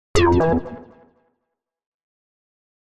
Scifi 16.mp3